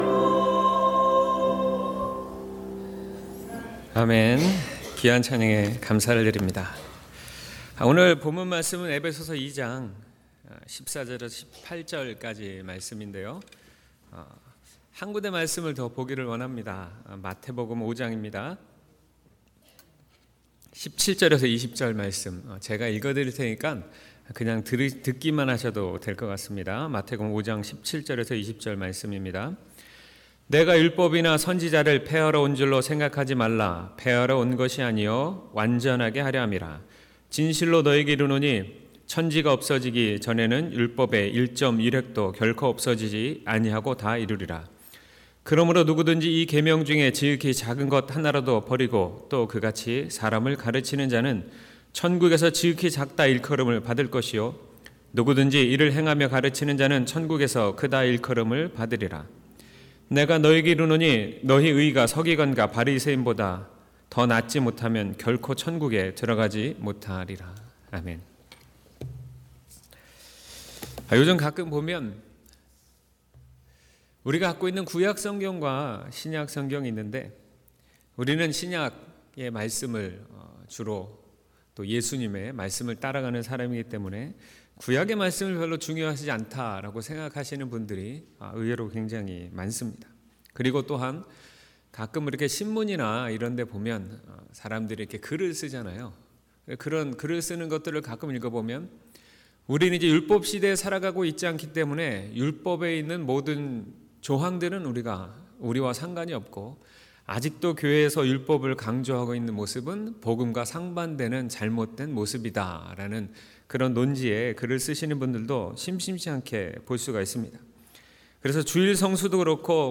3월17일 주일 예배 설교/사순절시리즈/율법, 복음, 화평, 그리고 완성